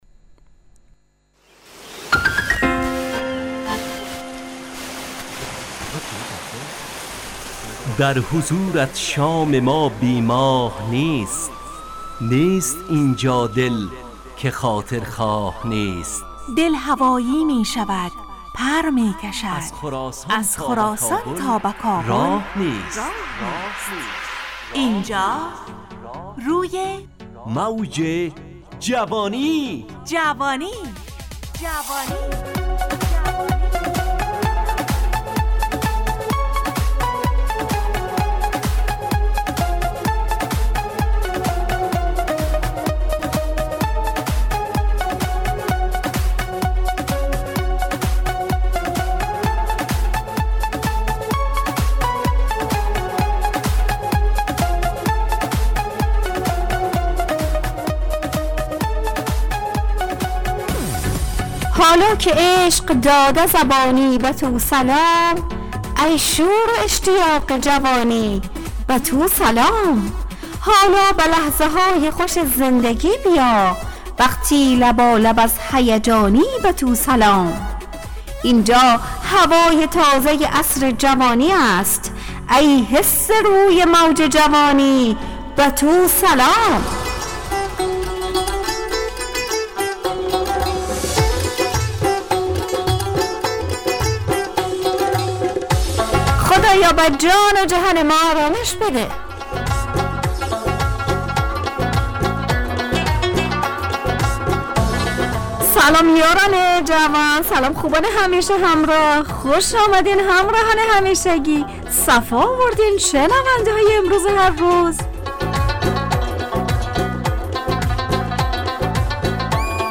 همراه با ترانه و موسیقی مدت برنامه 70 دقیقه . بحث محوری این هفته (قول و قرار) تهیه کننده